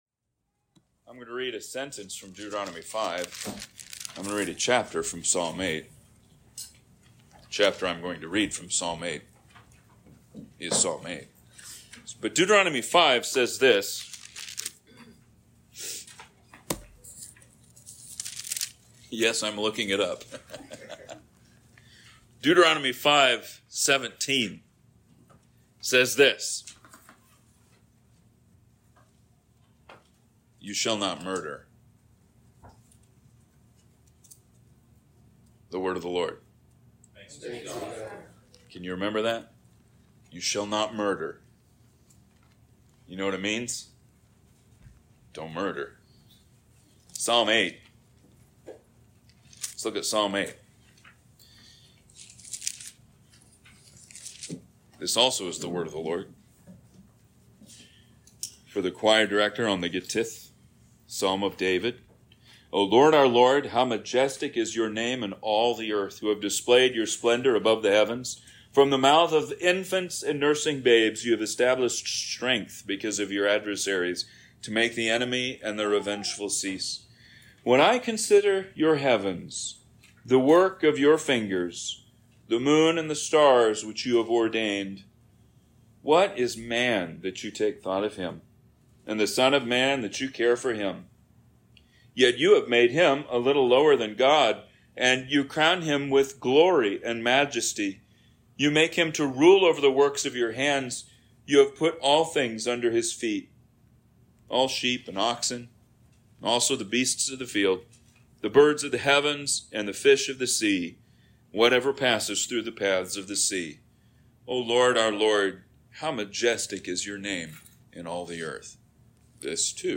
Sermons | Christ Church